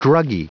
Prononciation du mot druggie en anglais (fichier audio)
Prononciation du mot : druggie